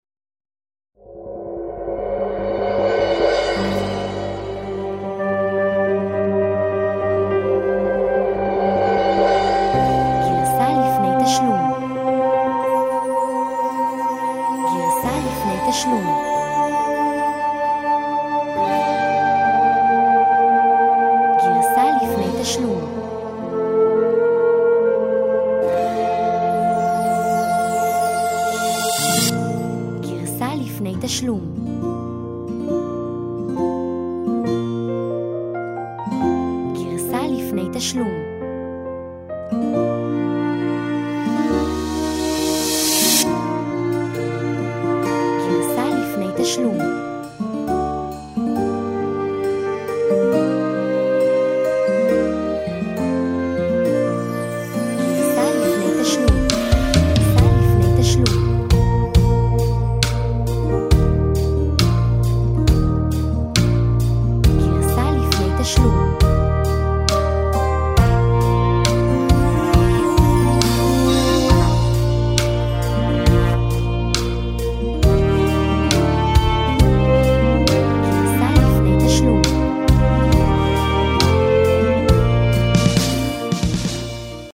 מחפש פלייבקים חסידיים איכותיים ומקצועיים?